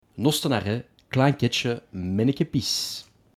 Les arrêts de la ligne de bus 48 annoncés en bruxellois
Les arrêts à bord de la ligne de la société bruxelloise de transport public (STIB) seront en effet déclamés en authentique bruxellois.
6. manneke pis.mp3